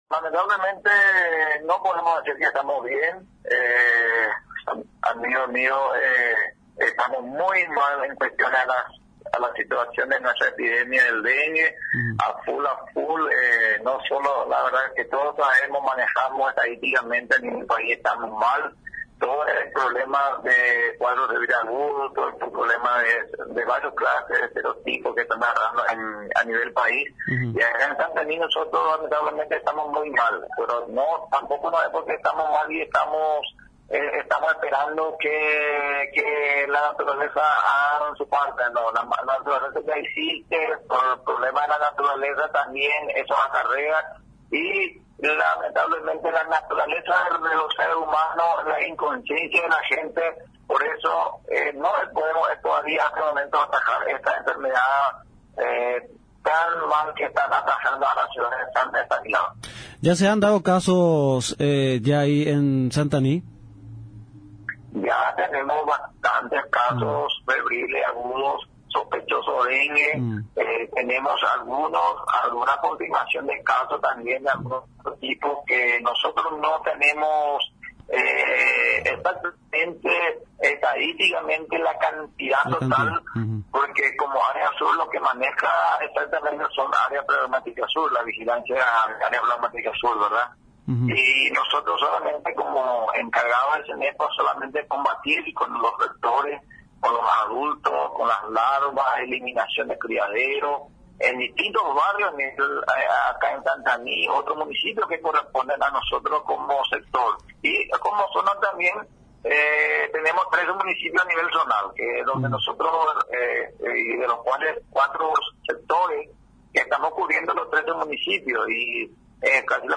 En entrevista a Radio Nacional San Pedro, exhortó a la ciudadanía a tomar conciencia para limpiar los patios y de esa manera eliminar los criaderos de mosquitos.